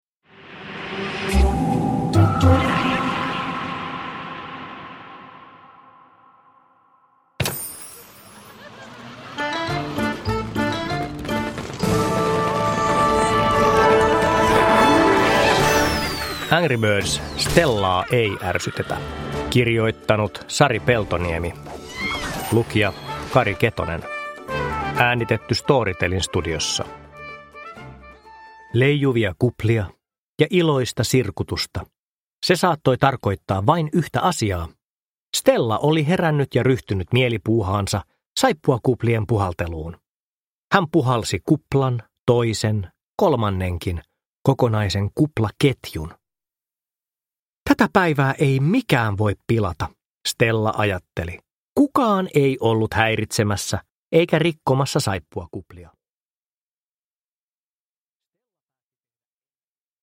Angry Birds: Stellaa EI ärsytetä! – Ljudbok – Laddas ner